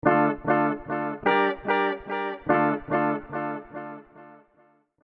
Psychedelic guitar instrument
• Psychedelic chords, licks and effects
• 40+ analog recorded electric guitar setups
Chords_-_Freesia_-_Beastsamples.mp3